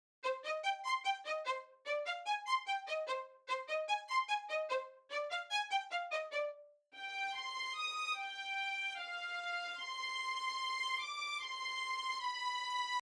Звук скрипок аля классика, нужна помощь.
Есть Kontakt Session Strings Pro и Logic Studio Strings. Остановился на лоджиковых скрипках, но призвук жуткий, как впрочем и на контактовских и очень резкий звук, как сделать его понежнее?